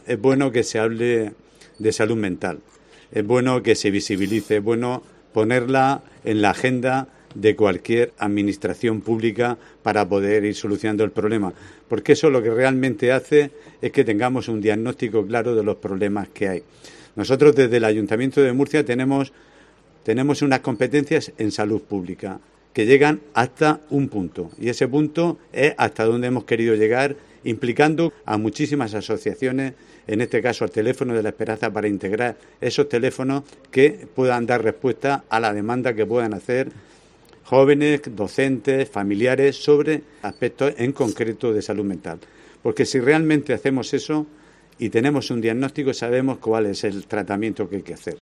José Antonio Serrano, alcalde de Murcia